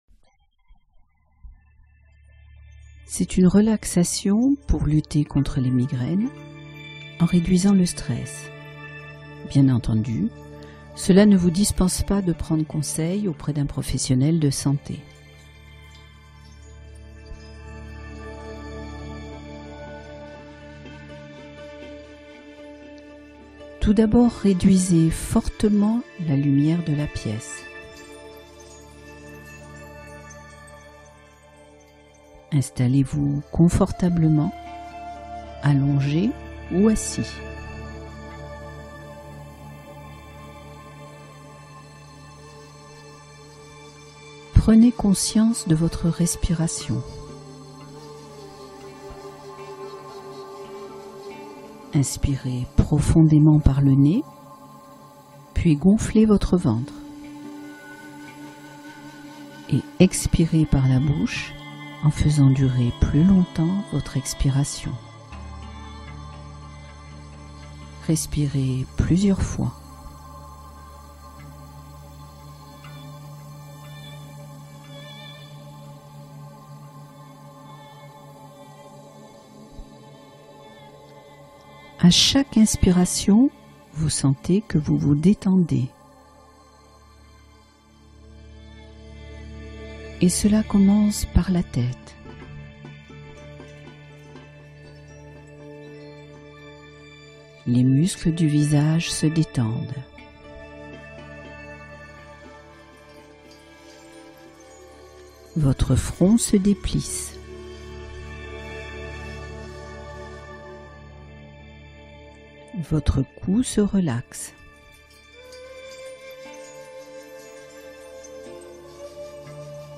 Relaxation guidée : apaiser la puissance des migraines